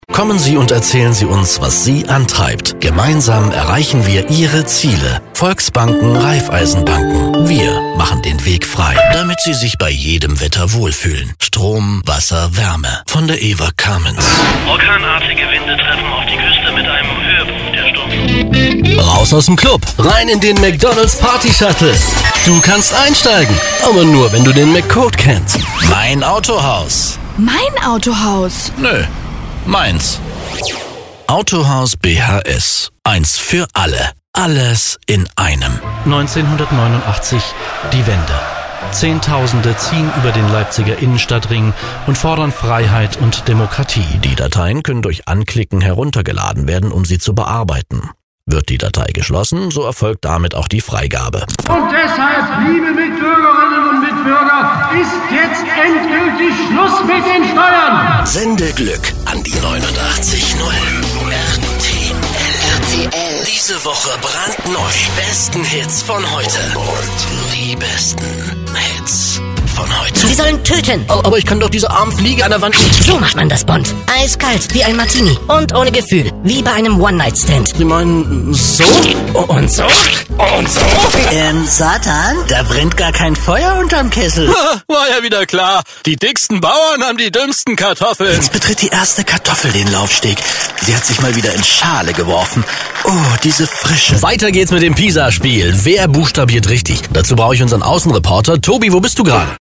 Professioneller Sprecher für Werbung, Präsentationen, e-learning, Funk, Film, TV & Kino, Werbespots, Voice Over, Multimedia, Internet, Industriefilm,
Sprechprobe: Werbung (Muttersprache):
german voice over artist